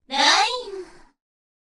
少し鼻にかかった女性の声で「ライン♥」と着信を知らせます。